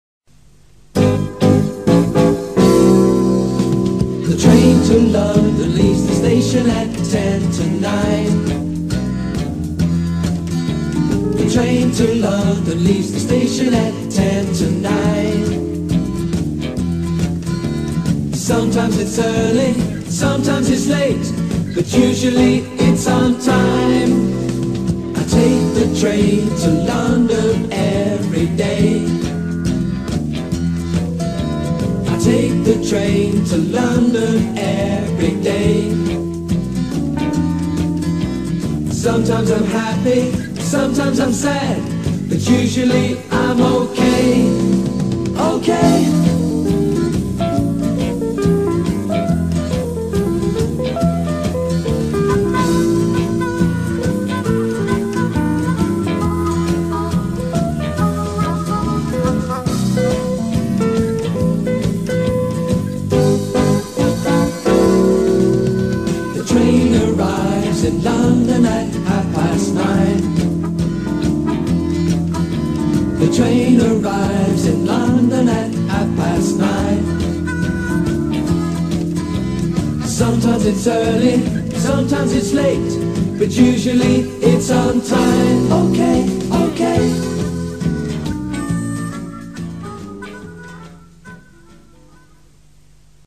Aprende Gramática Cantando
con esta canción no comercial